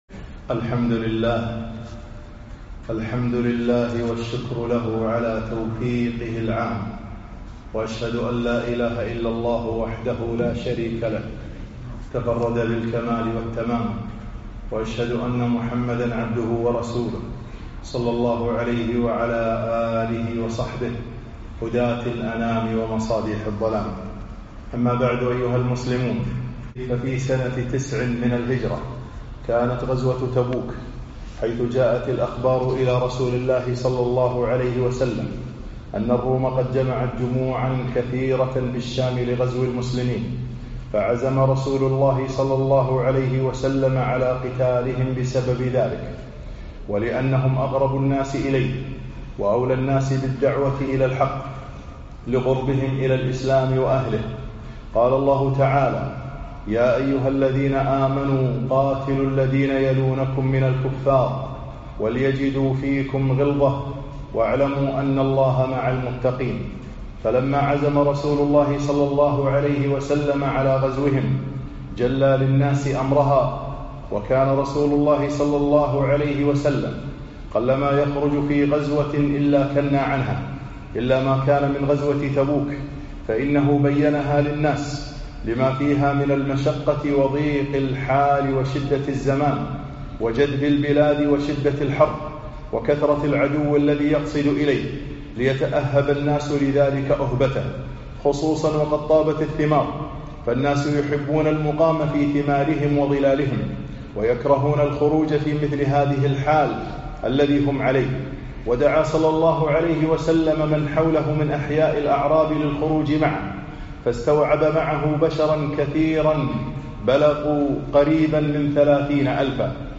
خطب السيرة النبوية 26